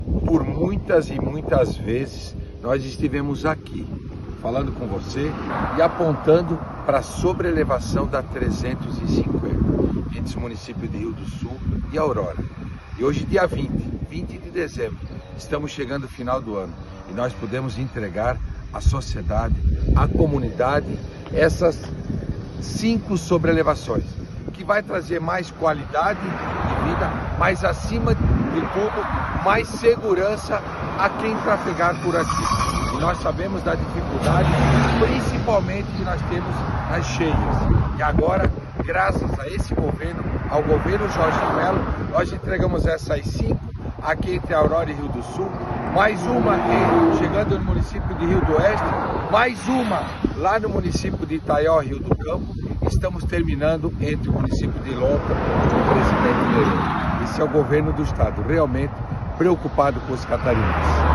Nesta sexta-feira, 20, o secretário da Infraestrutura e Mobilidade (SIE), Jerry Comper, vistoriou o local e falou sobre como ela vai alavancar o desenvolvimento da região:
SECOM-Sonora-Secretario-Infraestrutura-SC-350.mp3